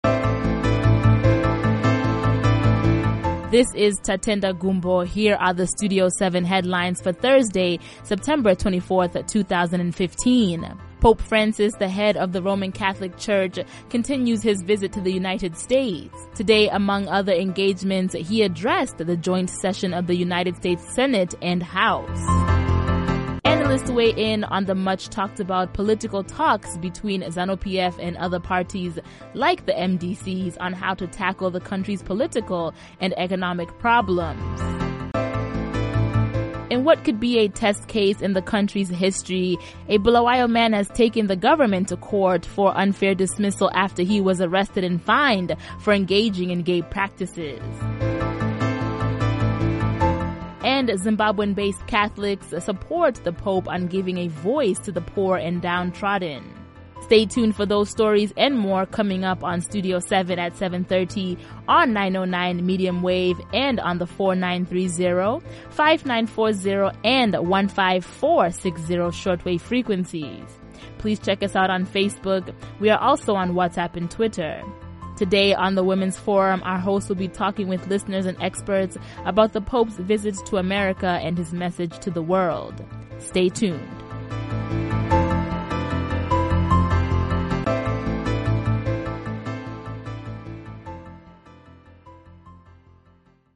Coverage Teaser